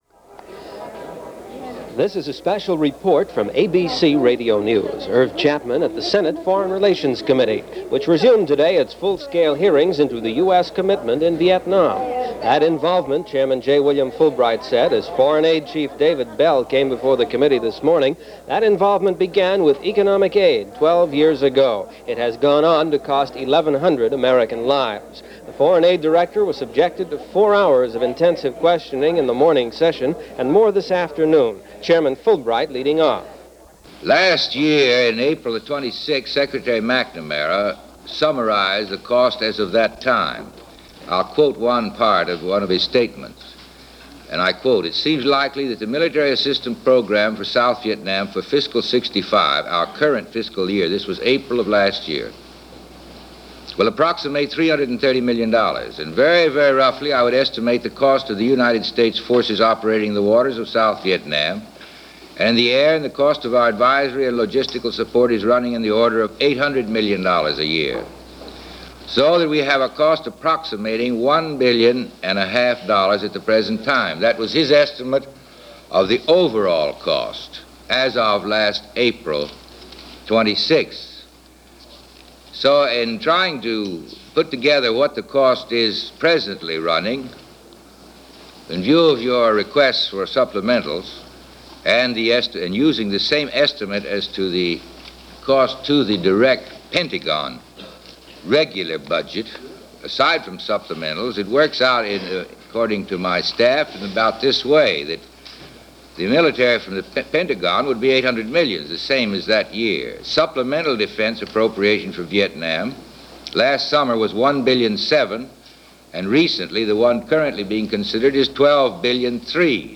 Vietnam War - Light At The End Of The Tunnel Was A Freight Train - 1966 - Past Daily Reference Room - Senate hearings on The Vietnam War.
ABC-Radio-Senate-Hearings-on-Vietnam-February-4-1966.mp3